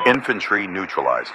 pilotKillInfantry1.ogg